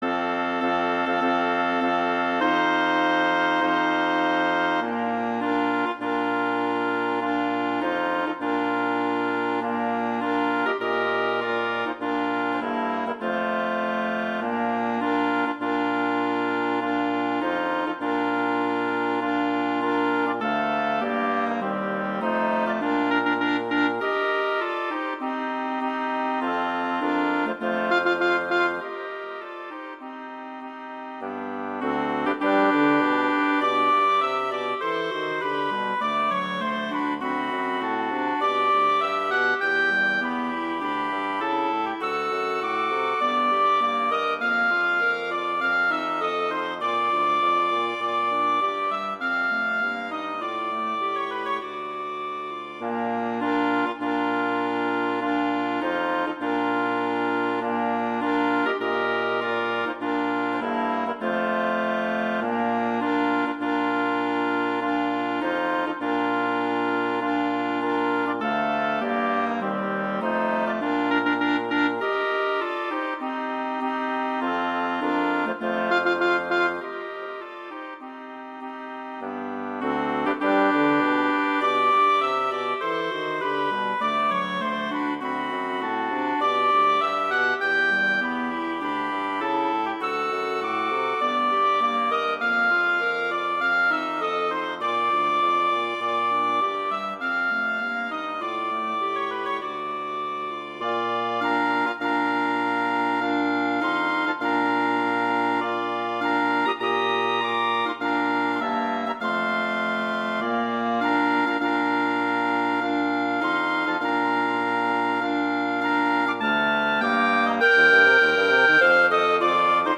Instrumentation: wind quartet
arrangements for wind quartet
for flute, oboe, clarinet in Bb and bassoon.
wedding, traditional, classical, festival, love, french